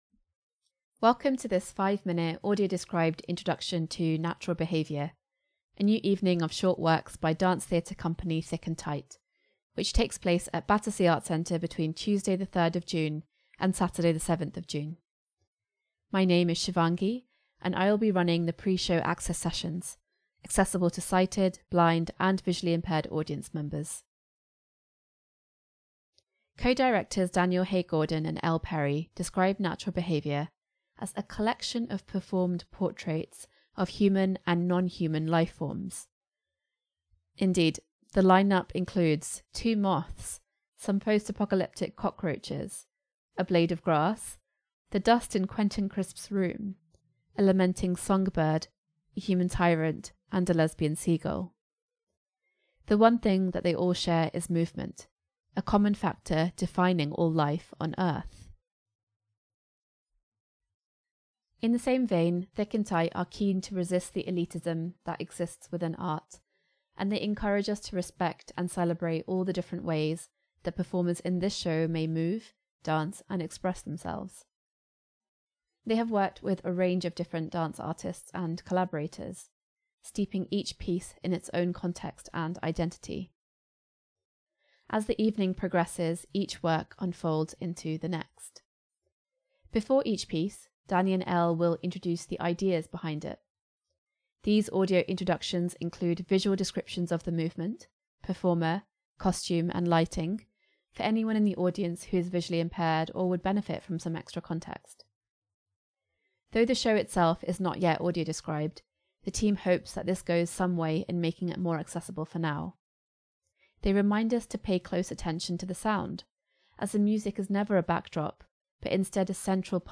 Audio Introduction
Natural-Behaviour-Audio-Introduction-1.mp3